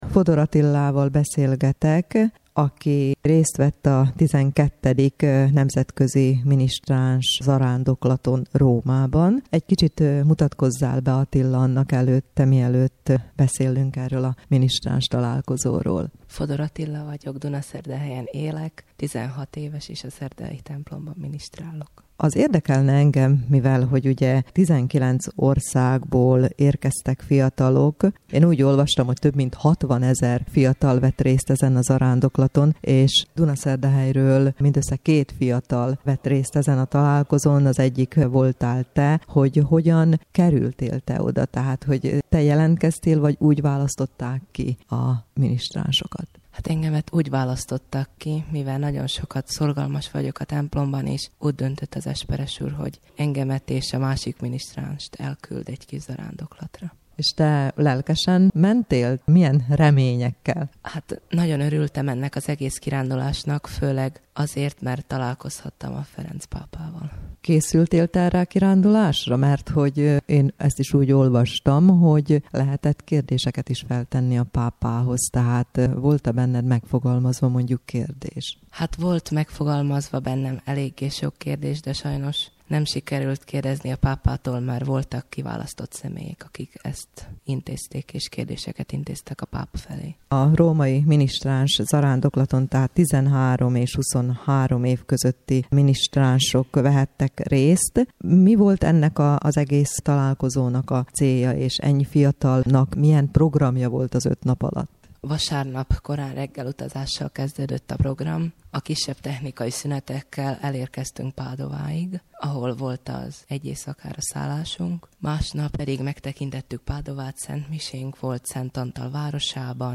Száz felvidéki magyar ministráns vett részt a Nemzetközi Ministráns Zarándoklaton. Vatican News Interjú